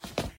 SkipWalk1.ogg